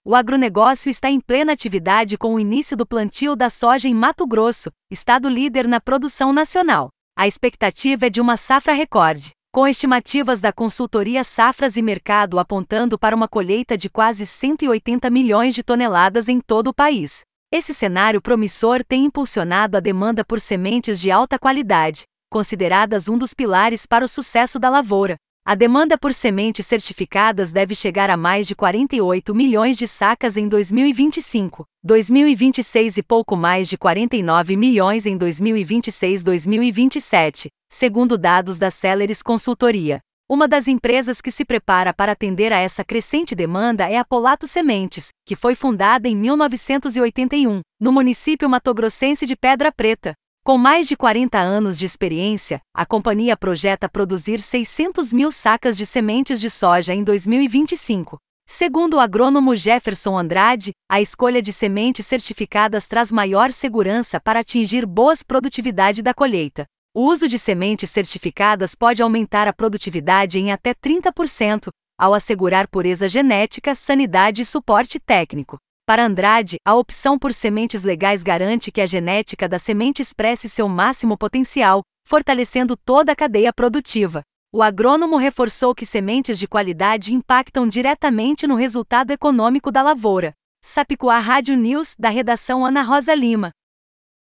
Sapicuá Comunicação – Assessoria e Produções em Áudio Radioagência Sápicuá de Notícias